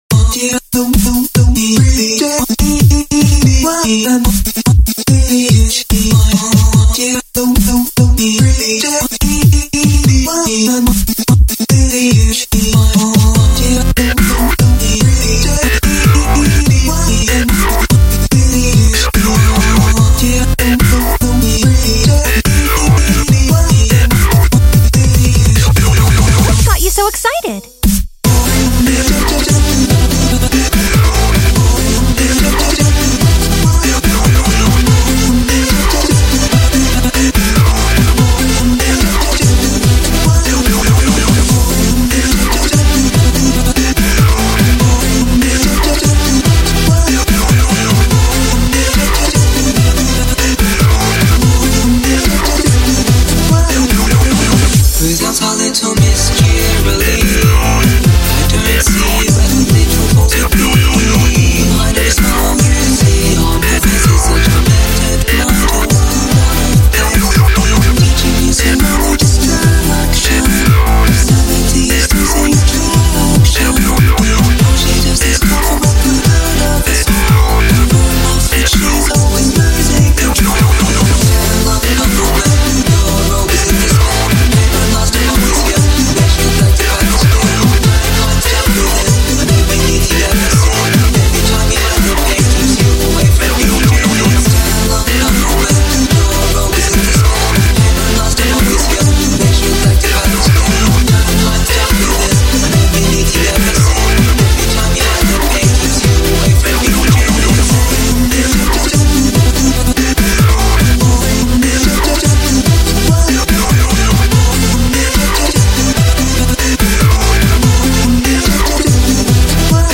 MY FIRST EVER TRY TO DO DUBSTEP OK? (TranceStep, what eva!)
The basses you hear are all custom made with NI Massive.